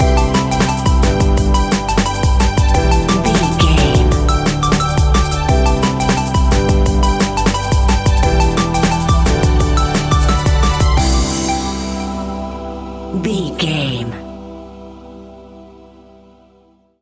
Ionian/Major
Fast
groovy
uplifting
bouncy
futuristic
electronic
sub bass
synth drums
synth leads
synth bass